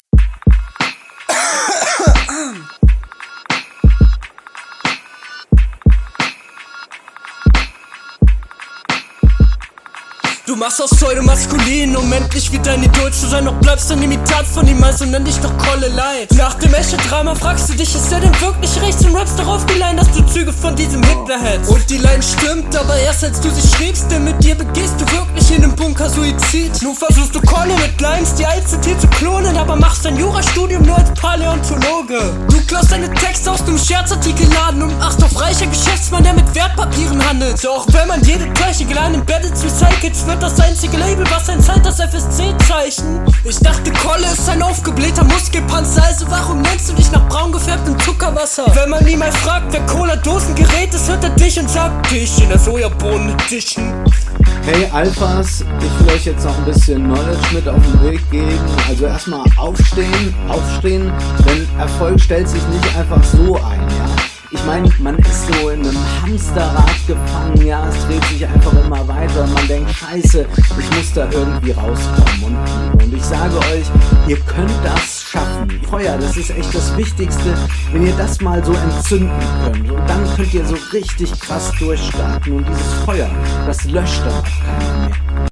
Definitiv besser auf deinen eigenen Beat, aber an dem Stimmeneinsatz müsste man noch arbeiten.